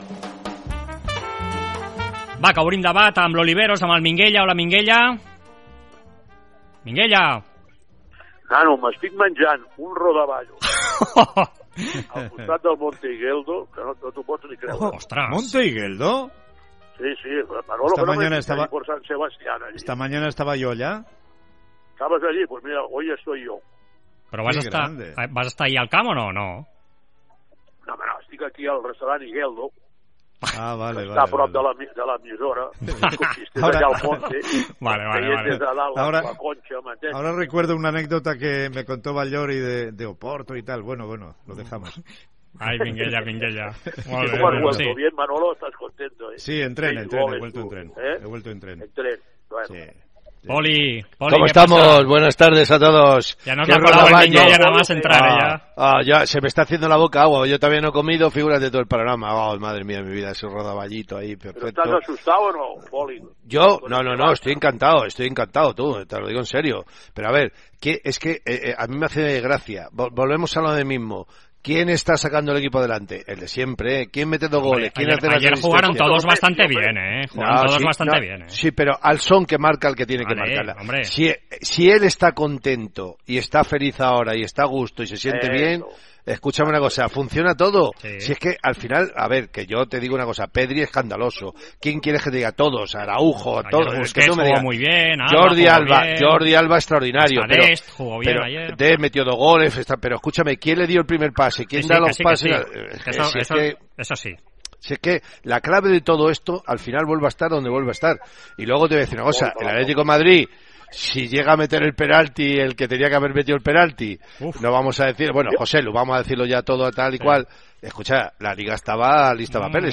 Escolta el 'Debat Esports COPE' amb Poli Rincón i Josep Maria Minguella analitzant la transformació de l'equip de Ronald Koeman.